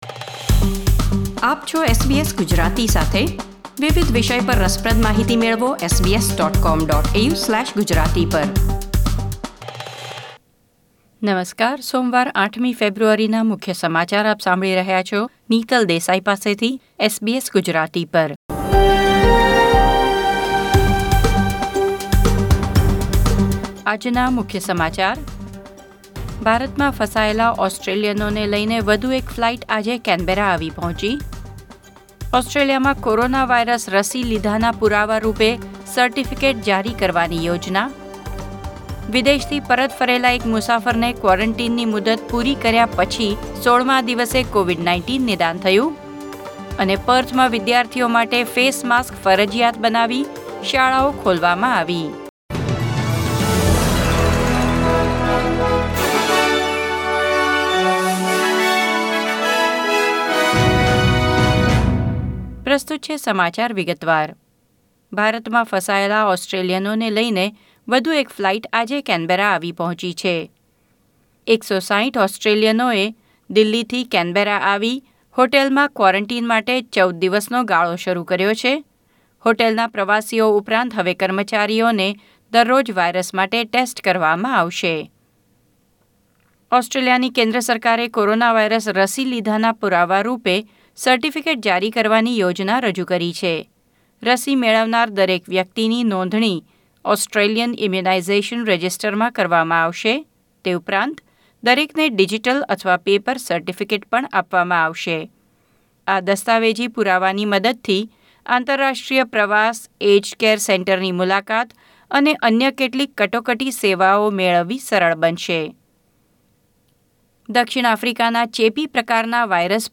SBS Gujarati News Bulletin 8 February 2021